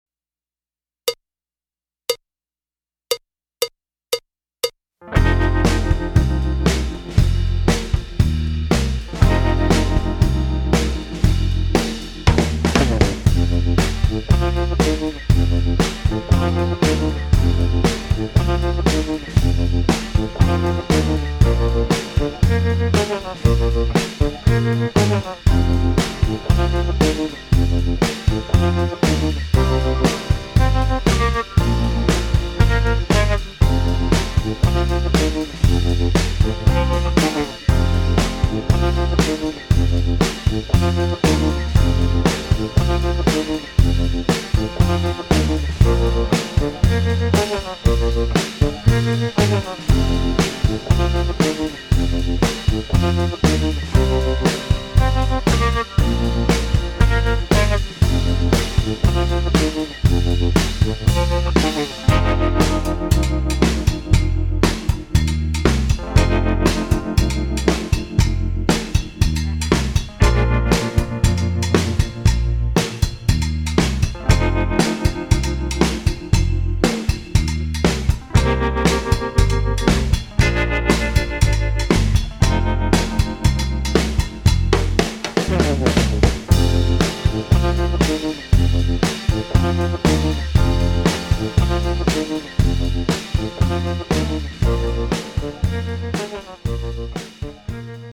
Taustaksi swamp-henkeä F:stä
divariskaba184-tausta.mp3